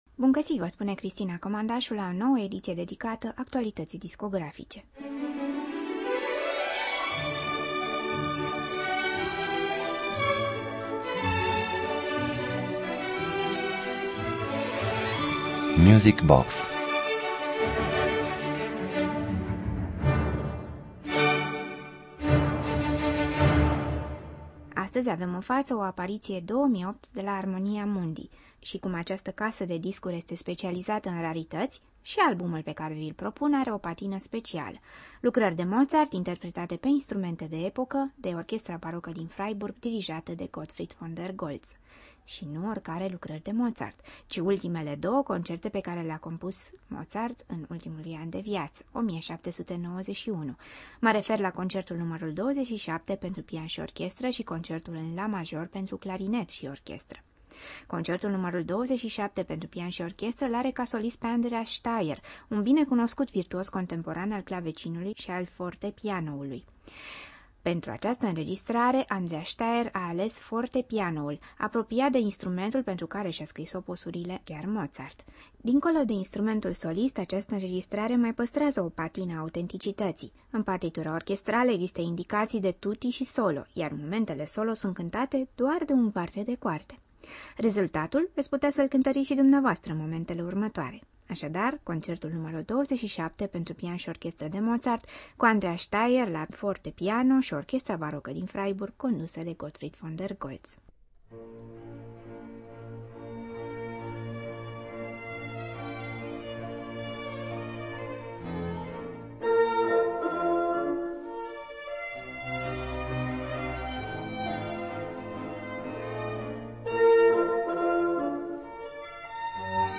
interpretate pe instrumente de epoca